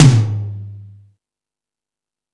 Metal Drums(28).wav